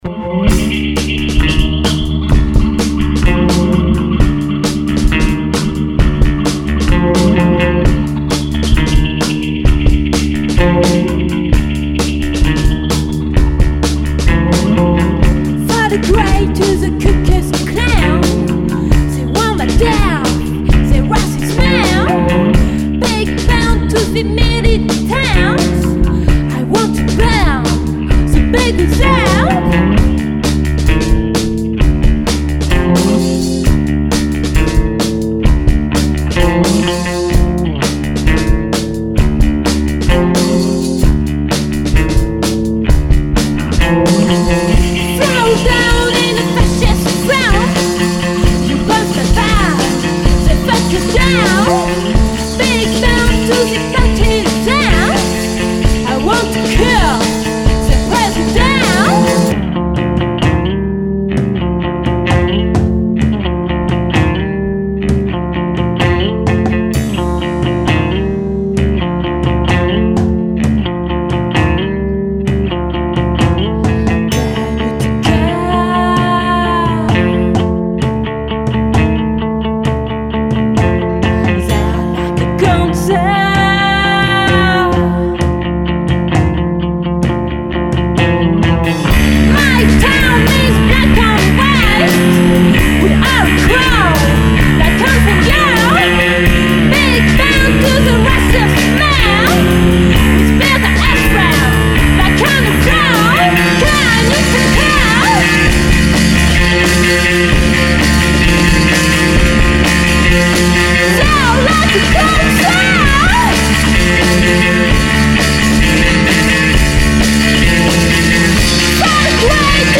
vocals
drums
both playing bass